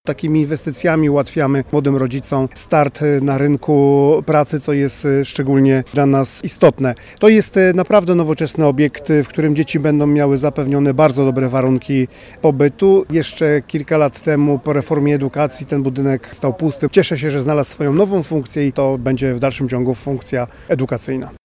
To już 21 placówka przedszkolna w Radomiu, Prezydent Radosław Witkowski dodaje: